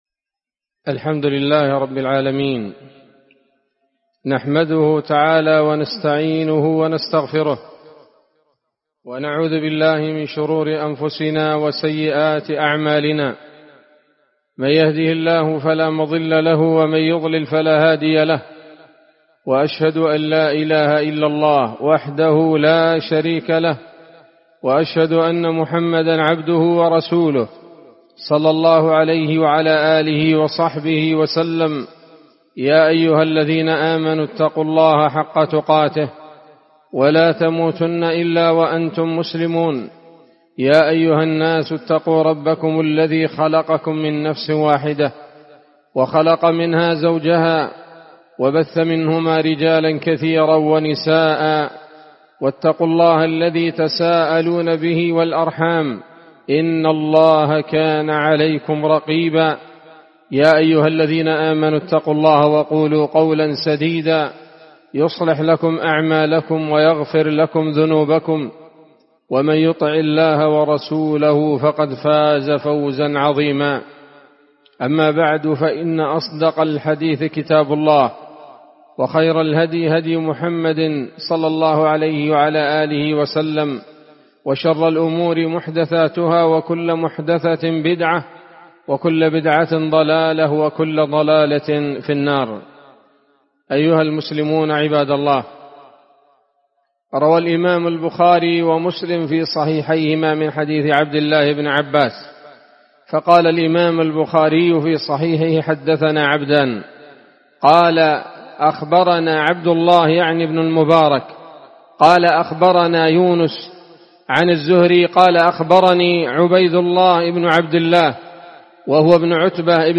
خطبة جمعة بعنوان: (( الجود والإحسان في شهر القرآن )) 7 رمضان 1446 هـ، دار الحديث السلفية بصلاح الدين